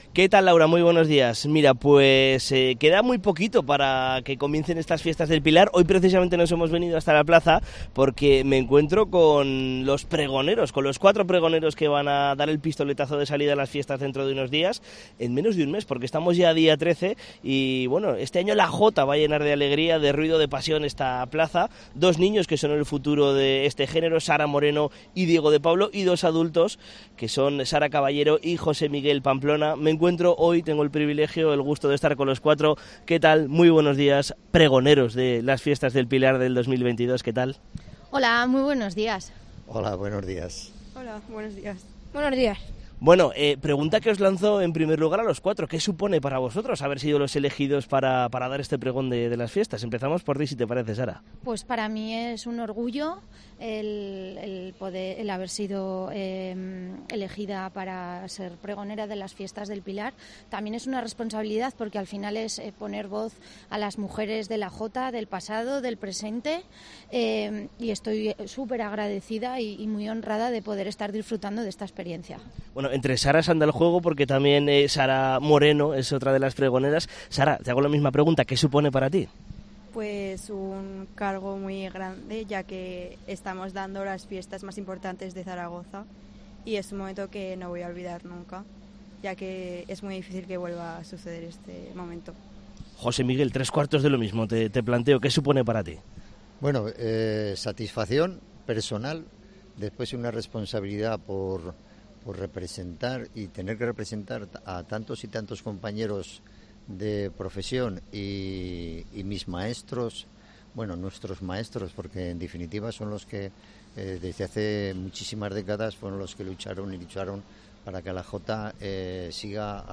Entrevista a los pregones del 'Pilar 2022'